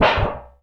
metal_tin_impacts_hit_hard_01.wav